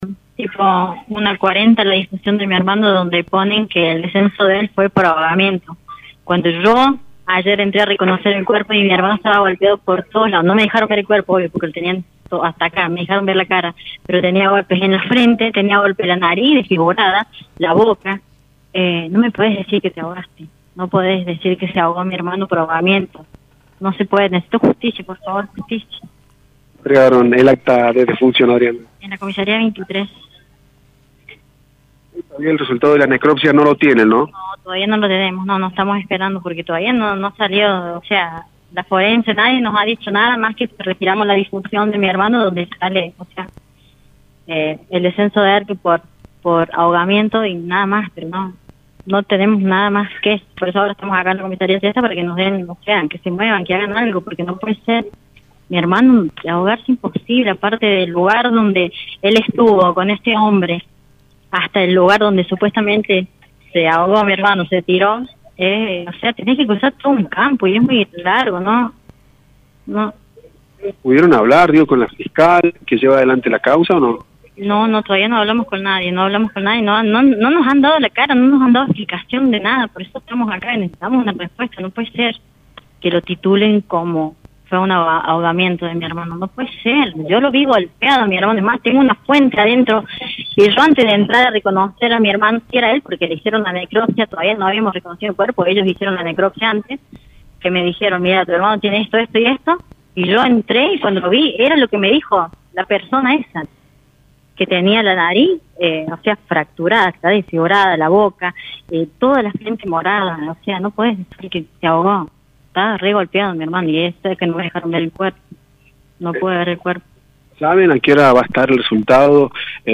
🎙 Entrevista